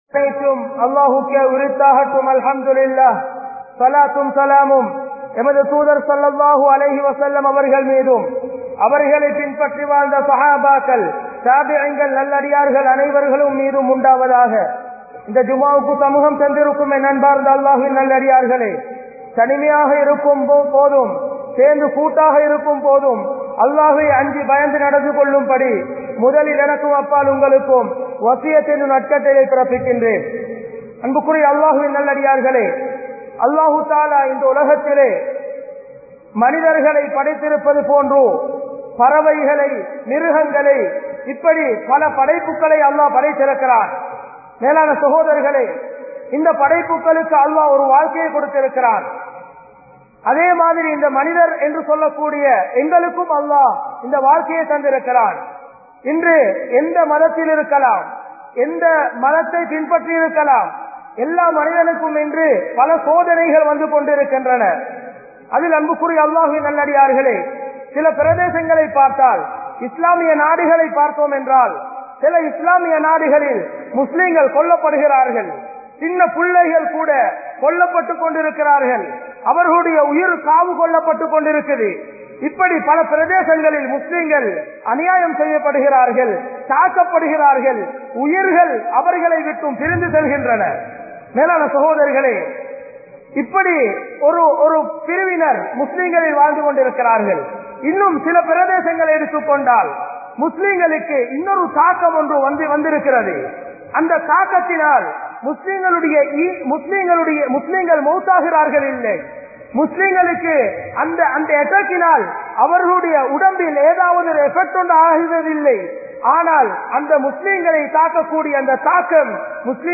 Eemaanai Parikkum MEDIA va? (ஈமானை பறிக்கும் ஊடகமா?) | Audio Bayans | All Ceylon Muslim Youth Community | Addalaichenai
Grand Jumua Masjitth